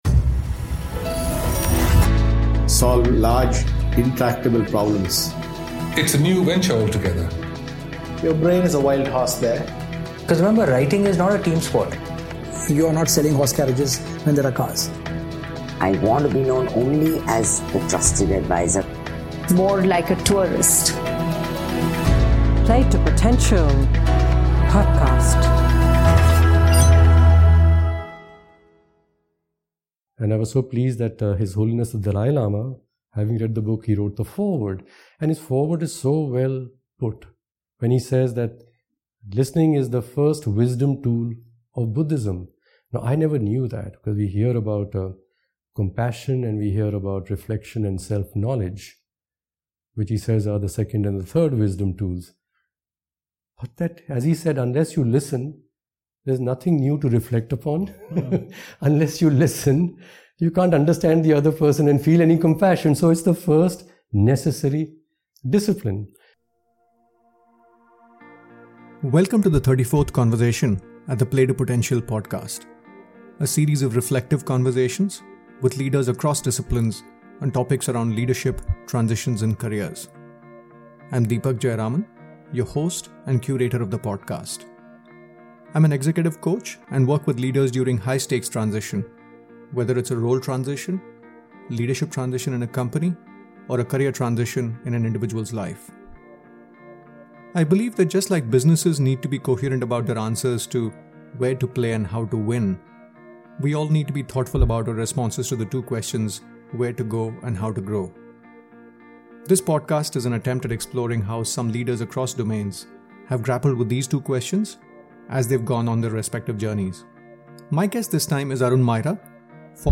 In our conversation, we discuss some of his key transitions post Tata Motors to the world of Advisory Services.